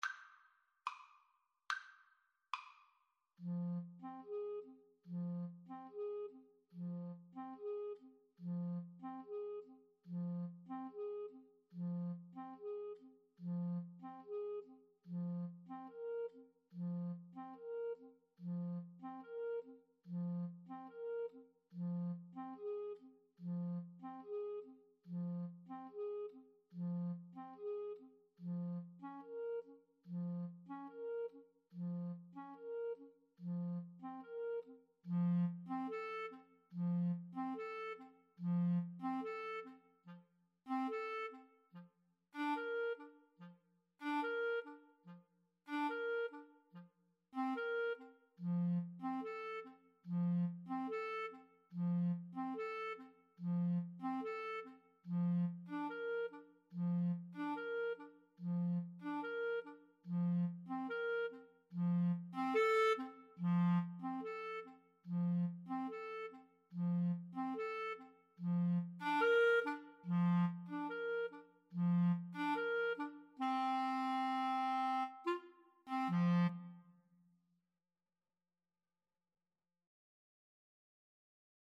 Classical (View more Classical Clarinet Duet Music)